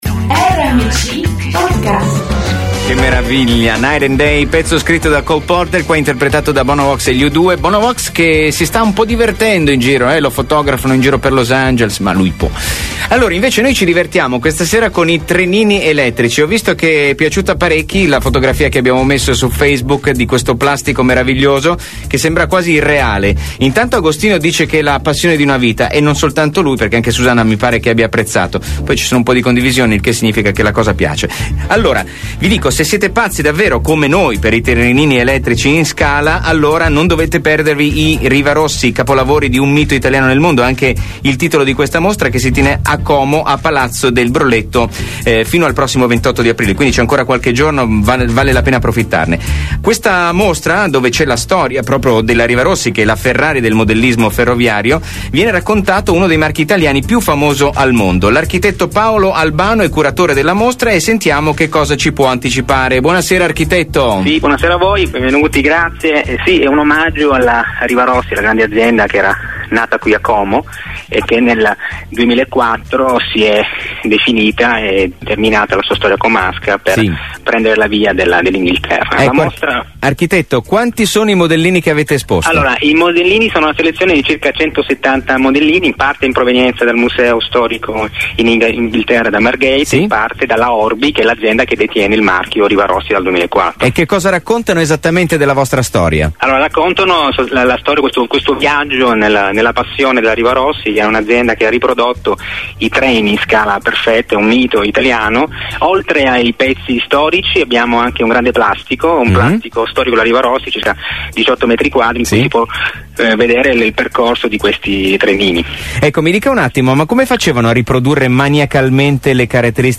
Nella sua intervista a RMC Radio Monte Carlo ci racconta la suggestione e la storia della mostra.